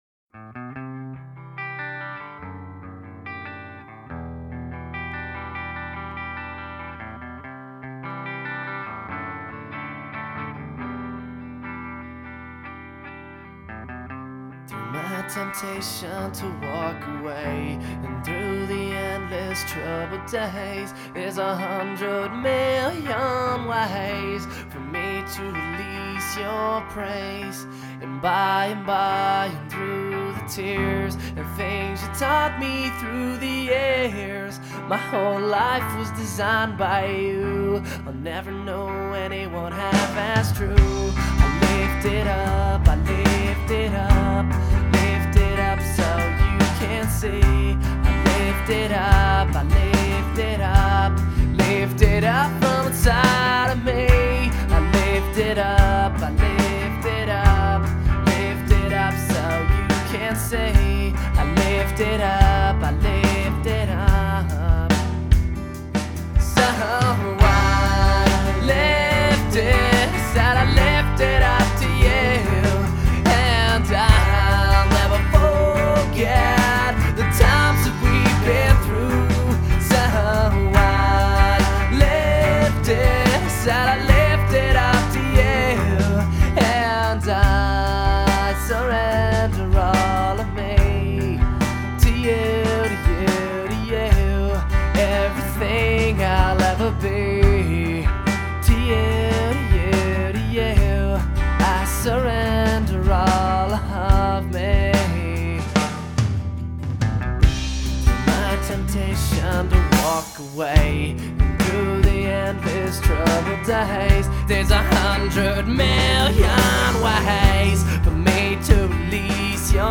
Hardrock